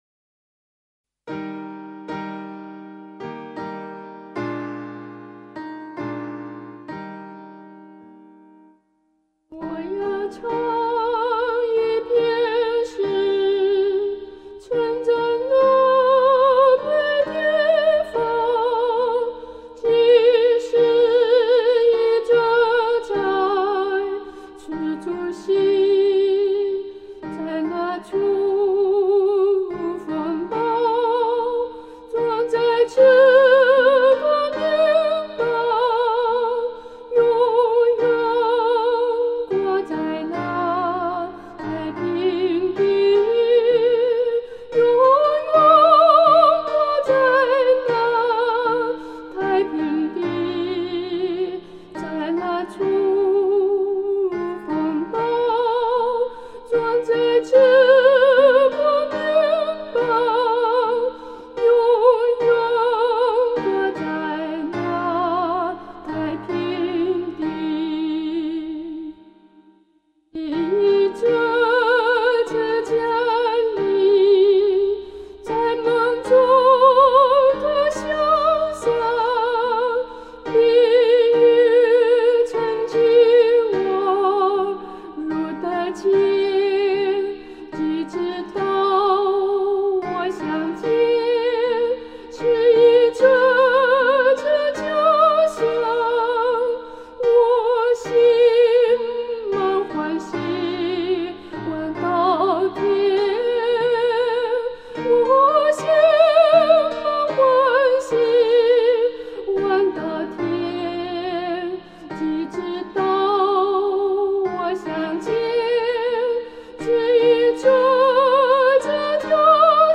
示唱